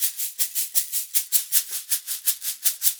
80 SHAK 03.wav